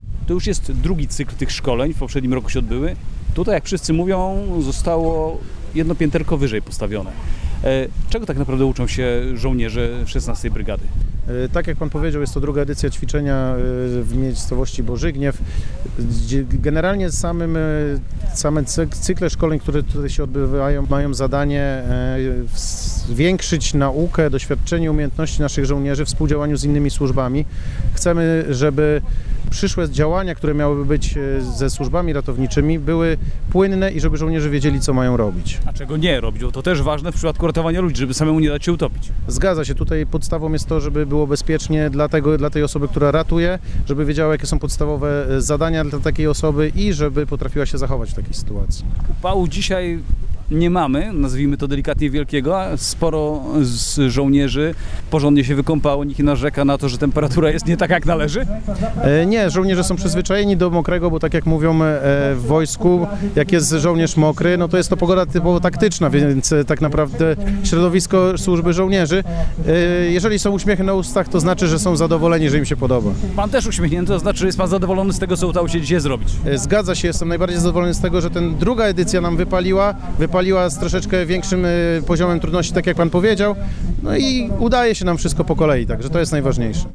Więcej na temat wspólnych, sobotnich ćwiczeń służb w Borzygniewie można się dowiedzieć z wypowiedzi ich uczestników.